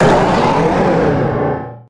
Grito de Mega-Salamence.ogg
Grito_de_Mega-Salamence.ogg.mp3